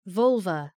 Προφορά
{‘vʌlvə}